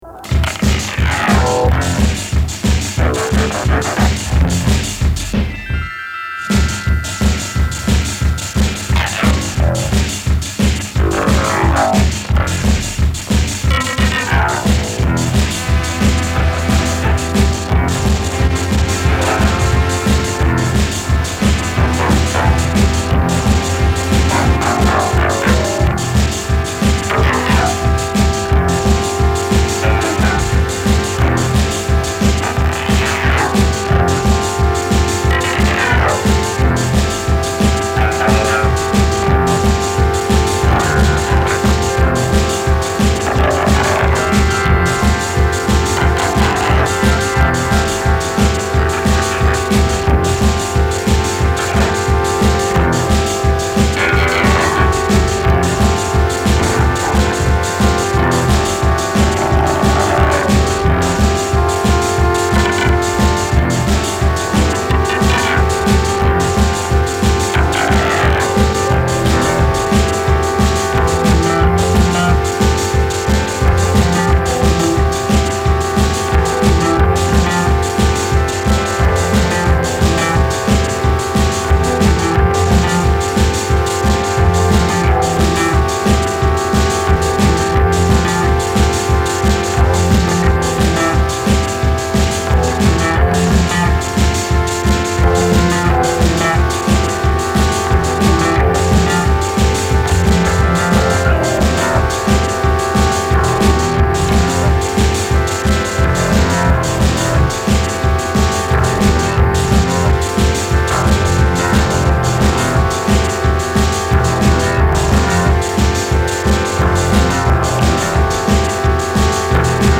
Drum loop, bass, and keys.